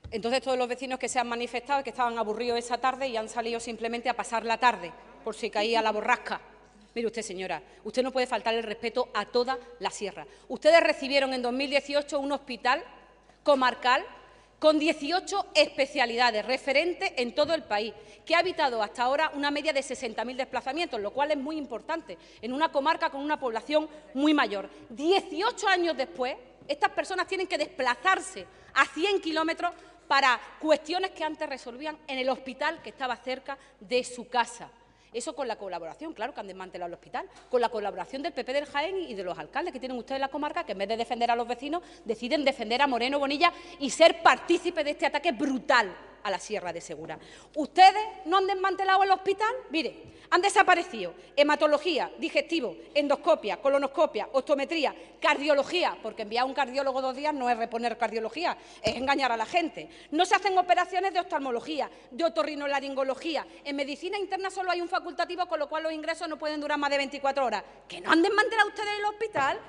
En el Parlamento de Andalucía
Cortes de sonido